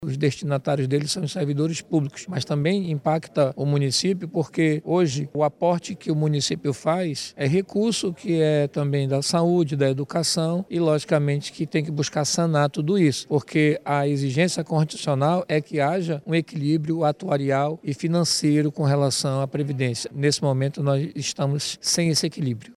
O presidente da CCJR, o vereador Gilmar Nascimento, disse que o objetivo da propositura é garantir o equilíbrio financeiro do sistema.